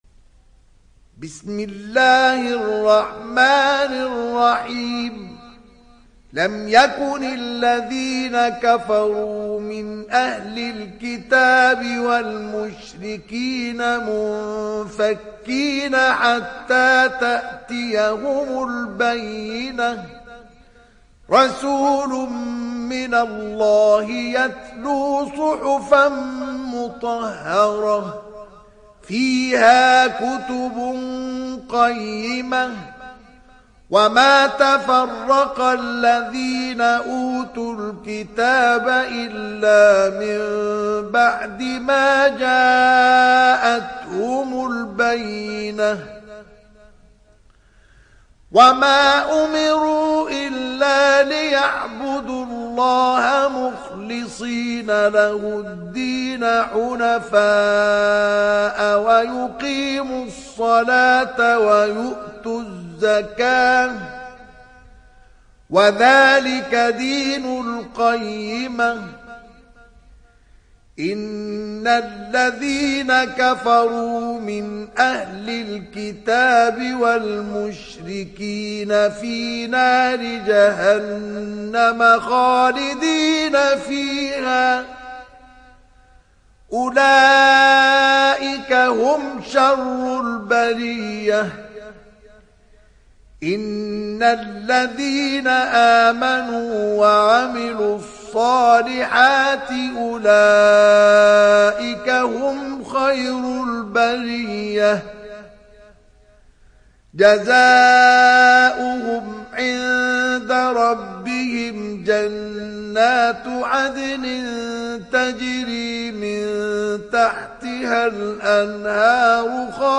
تحميل سورة البينة mp3 بصوت مصطفى إسماعيل برواية حفص عن عاصم, تحميل استماع القرآن الكريم على الجوال mp3 كاملا بروابط مباشرة وسريعة